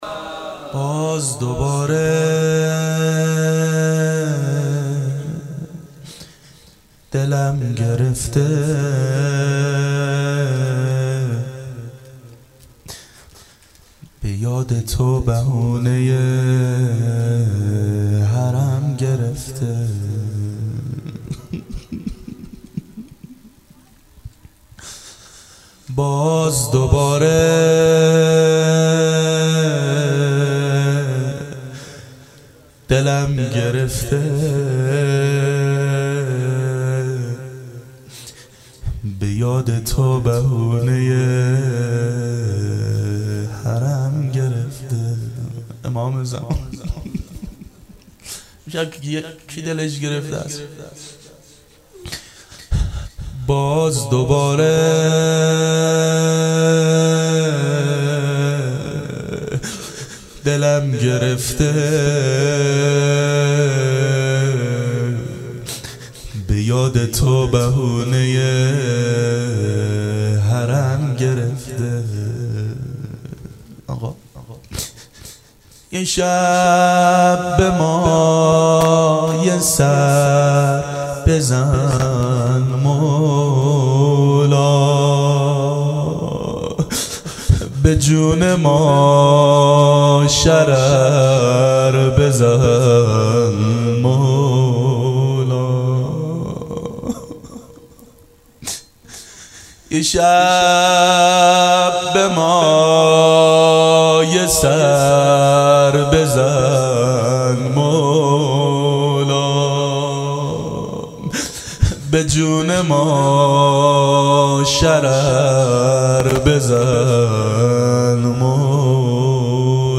باز دوباره دلم گرفته.مناجات باامام زمان علیه السلام
• دهه اول صفر سال 1390 هیئت شیفتگان حضرت رقیه س شب دوم